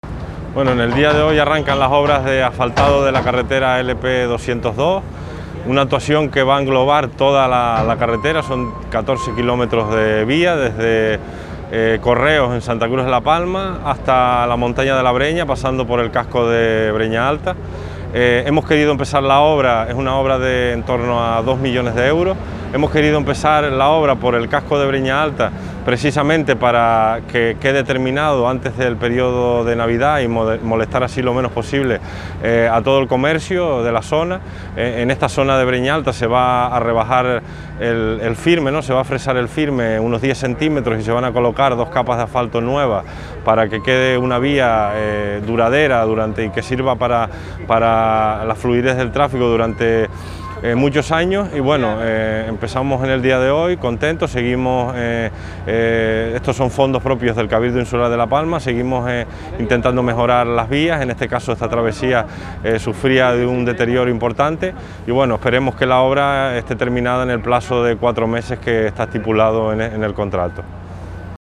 Declaraciones Borja Perdomo.mp3